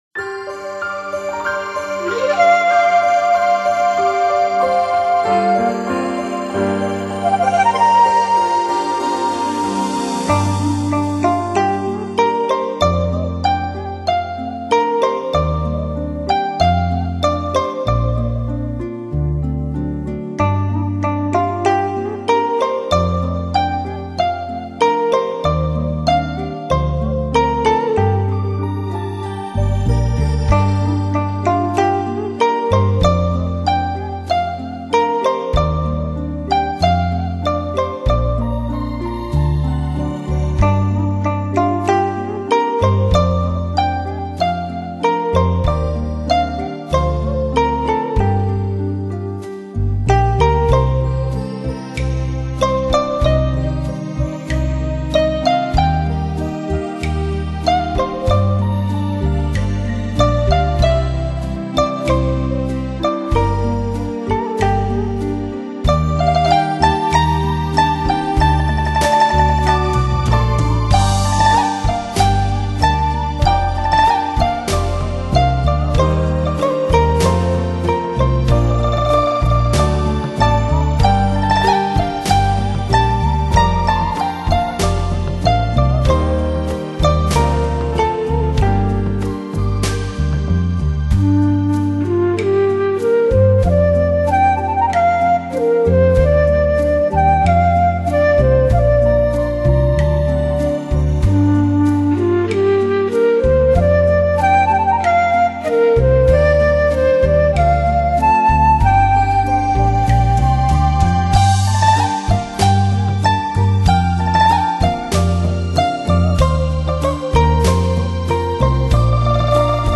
像这样的中西合并的钢琴和古筝合奏很少见。